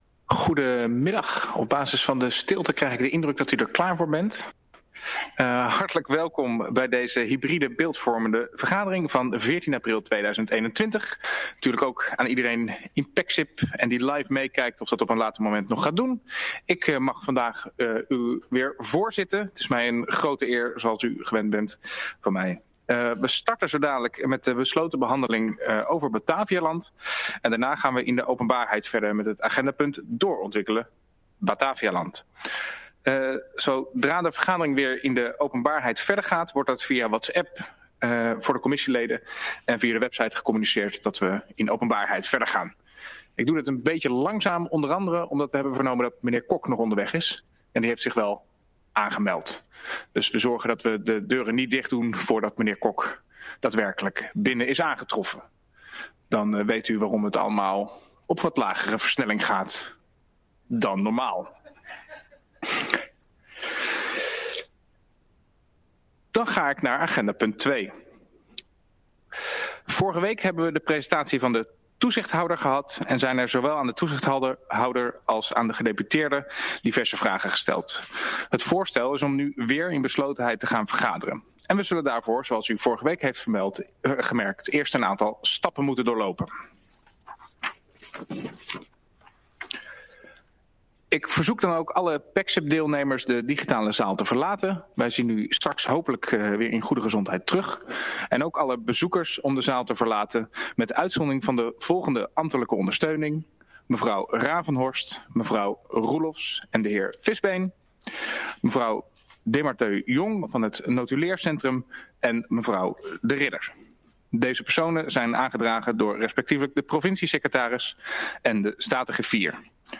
De agenda is aangepast n.a.v. beeldvormende sessie EMS van 7 april 2021Hybride: Fysiek (besloten deel) + Videovergadering 2 (Pexip)